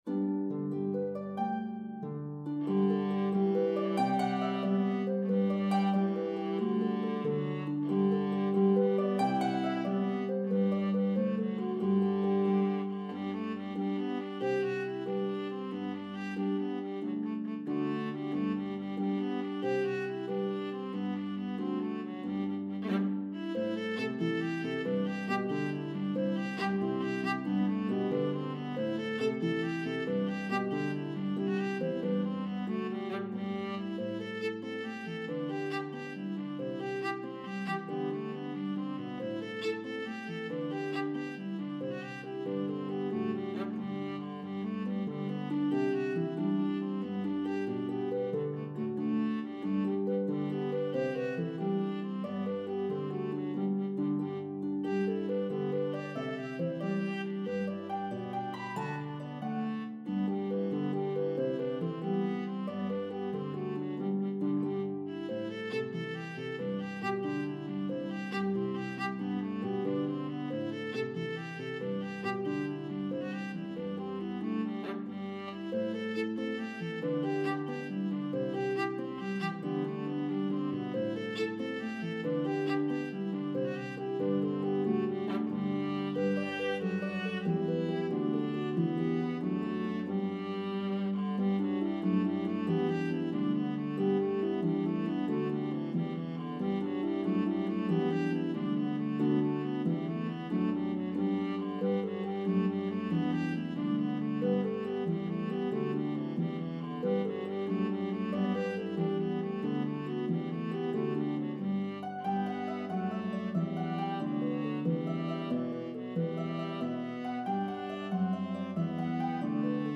a medley of two joyful, upbeat Irish Jigs